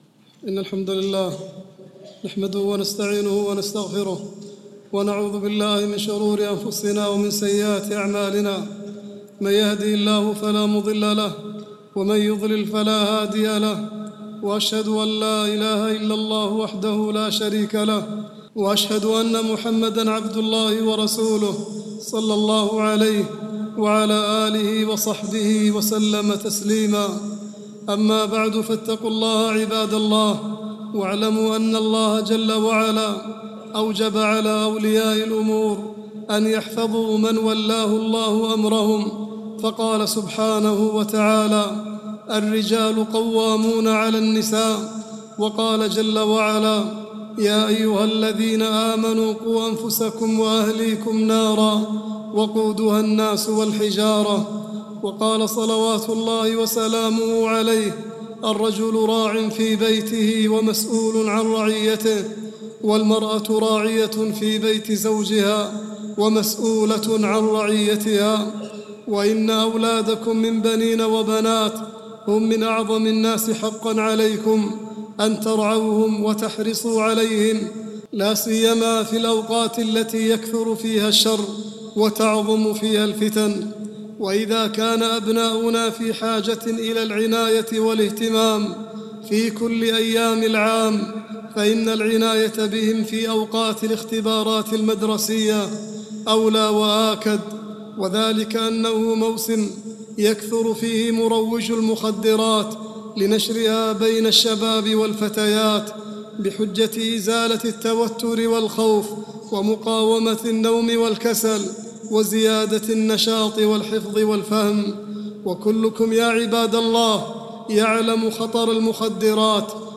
khutbah-15-8-38.mp3